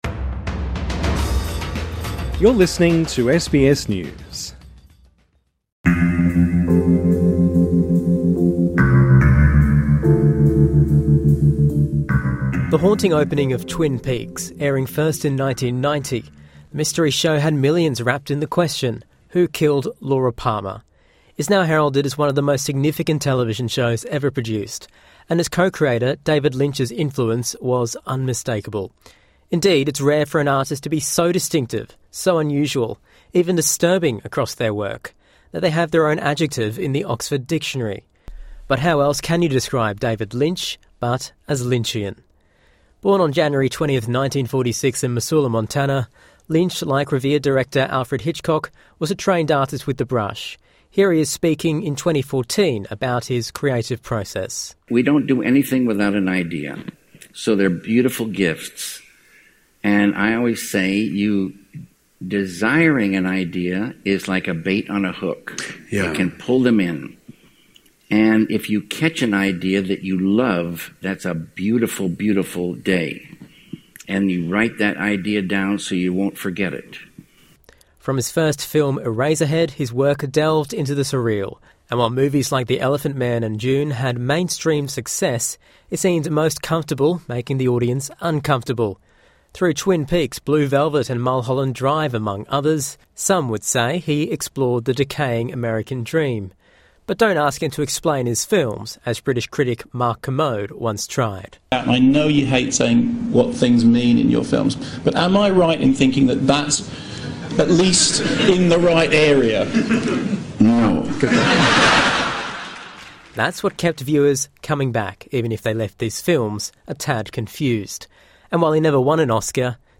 Here he is speaking 2014, about how his creative process.